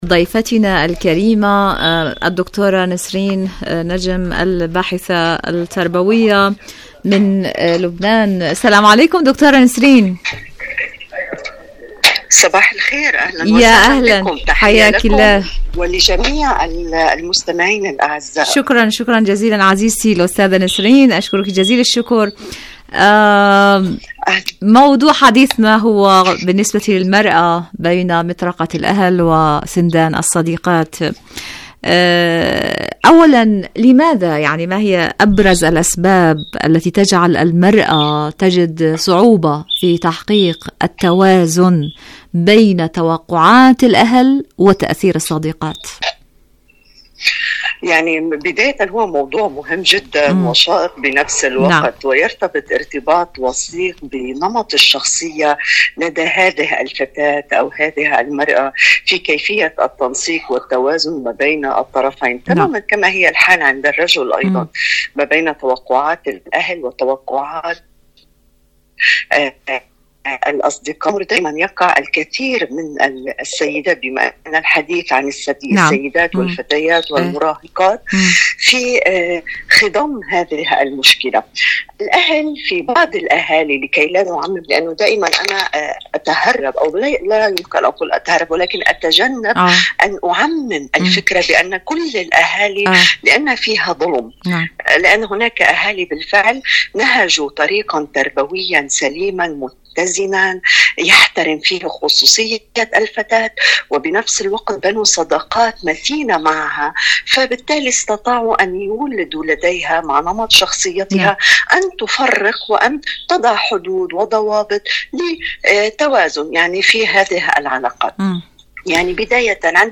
مقابلات برامج إذاعة طهران العربية برنامج عالم المرأة المرأة مقابلات إذاعية المرأة بين مطرقة الأهل وسندان الصديقات الصداقة بين النساء شاركوا هذا الخبر مع أصدقائكم ذات صلة قوة الدبلوماسية والمواقف الإيرانية..